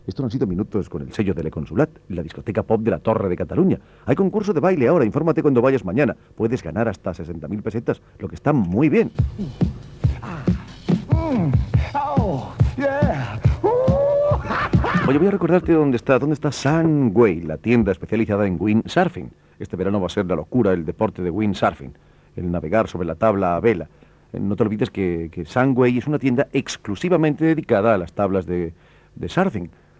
Publicitat de la dsicoteca Le Consulat i de la botiga de "windsurf" Sunway
Musical
FM